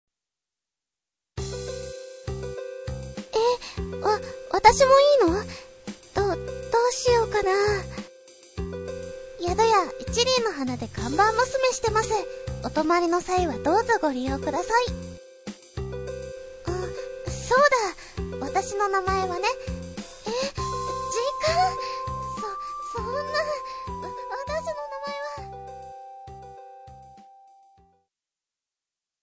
【ＣＶ】
自己紹介ボイス"